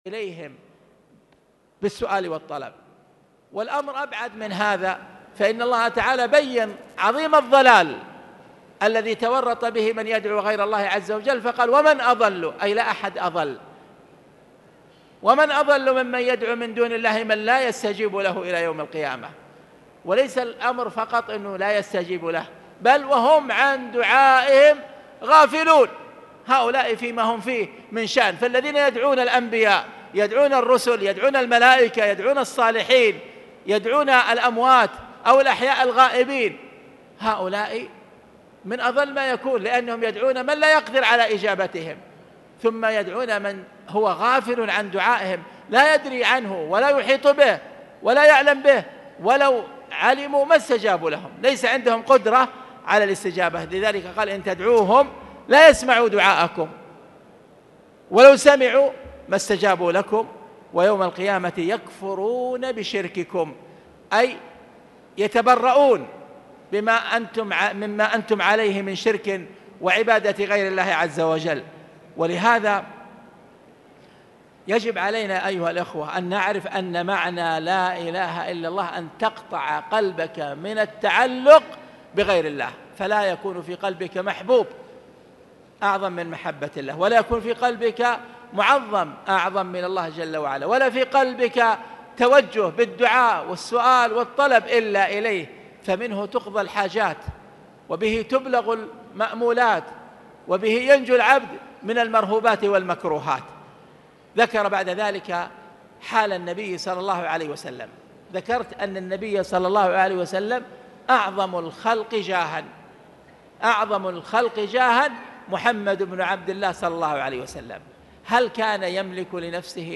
بداية الدرس مقطوع باب قوله تعالى: {أيشركون مالا يخلق شيئا وهم يخلقون}
تاريخ النشر ٣٠ ربيع الأول ١٤٣٨ هـ المكان: المسجد الحرام الشيخ